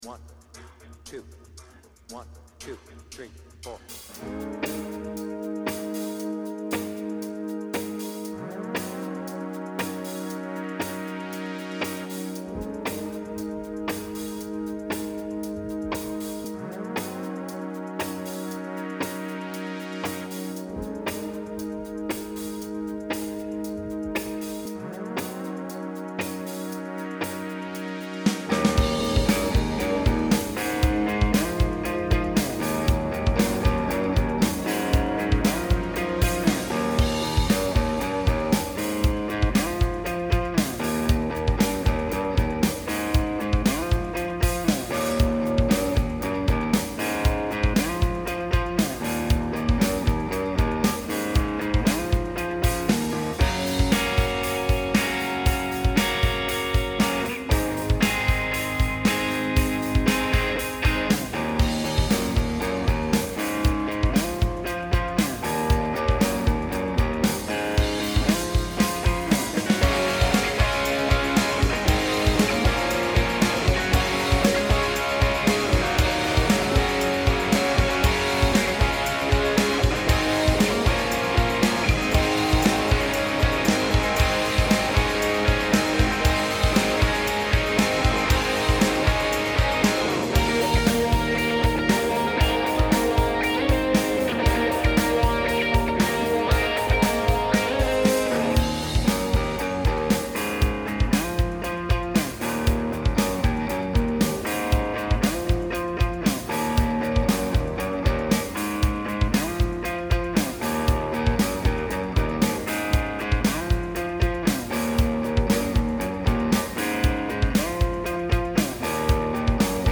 BPM : 116
Tuning : Eb
Without vocals